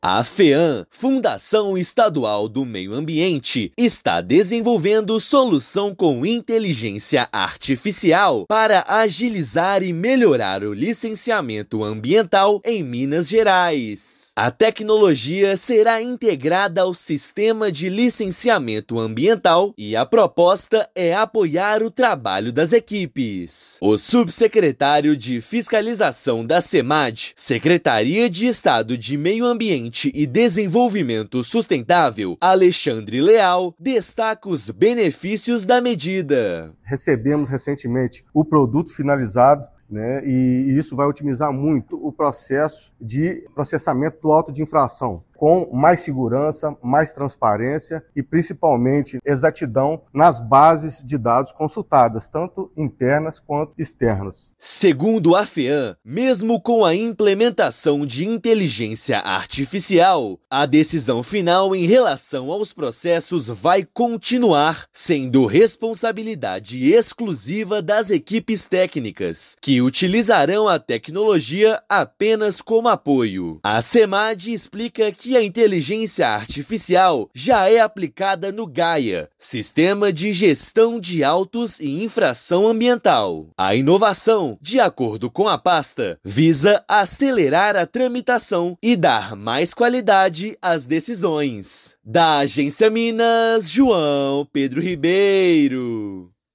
Tecnologia vai acelerar a análise de processos, reduzir prazos e tornar a gestão ambiental mais eficiente em todo o Estado. Ouça matéria de rádio.